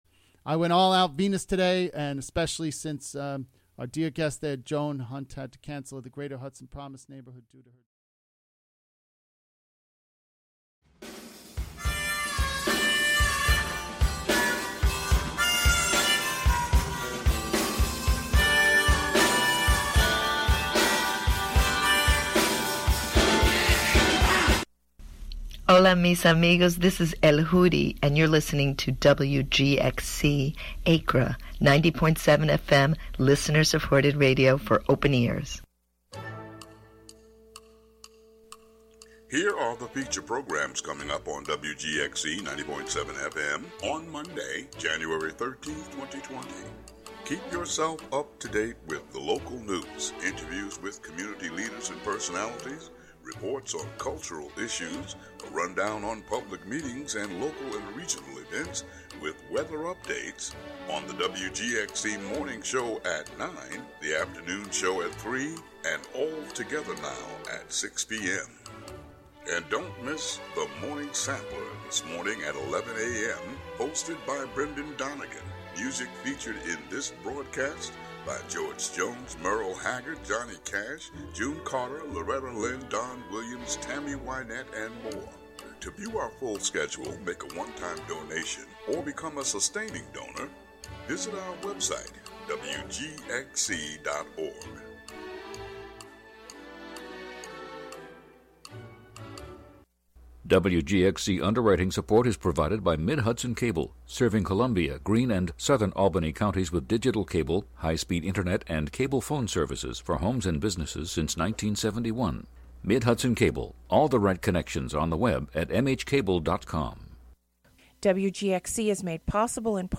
The Morning Sampler is an eclectic mix of old and new blues, electronica, pop, RnB, country, Latin, rock, rap and a bit of classical music.